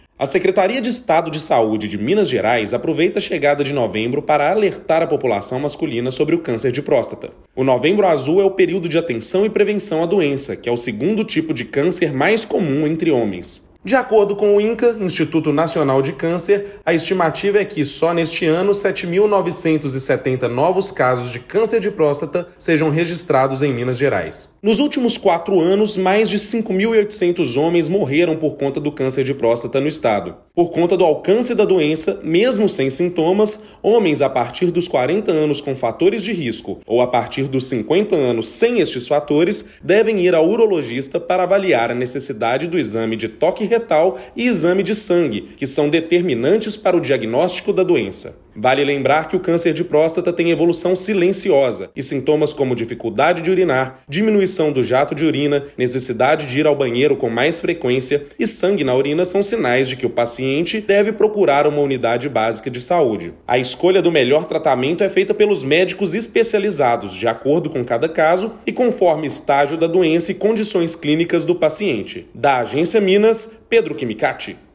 Com a chegada do mês de novembro, a Secretaria de Estado de Saúde de Minas Gerais (SES-MG) chama a atenção da população masculina.  Ouça matéria de rádio.